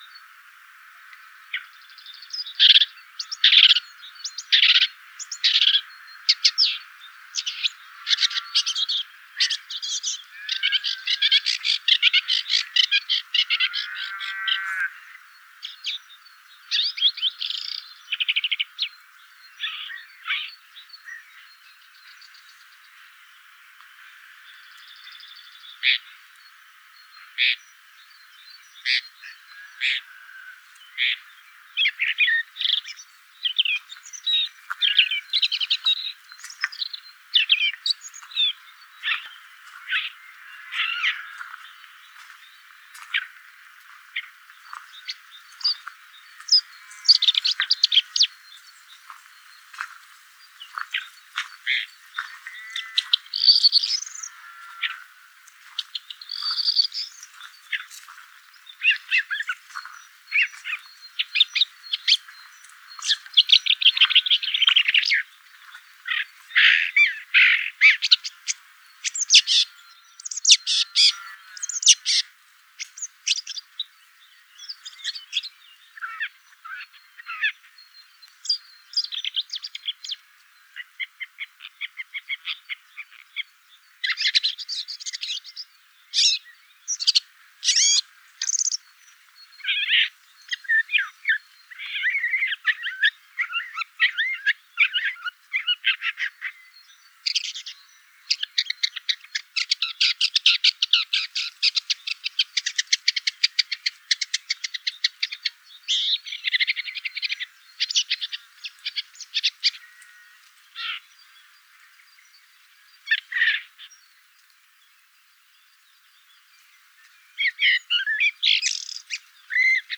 Lanius senator - Woodchat shrike - Averla capirossa